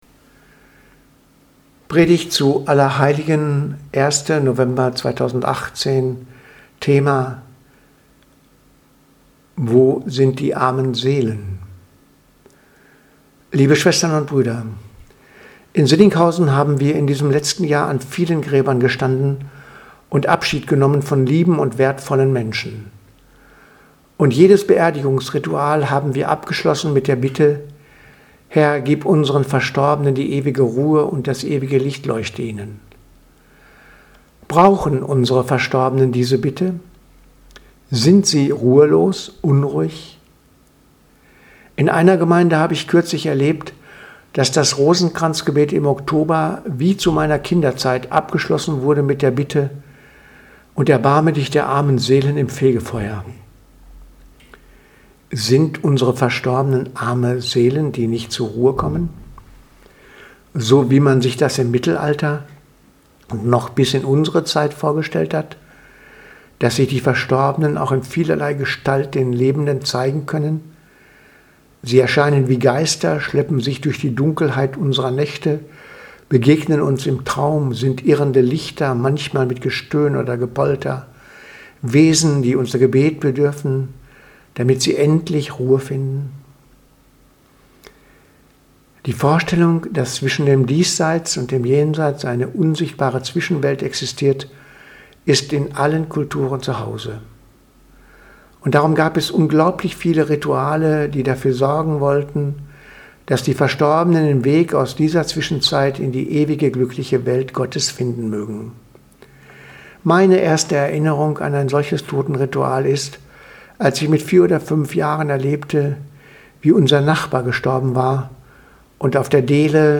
Predigt vom 1.11.2018 – Allerheiligen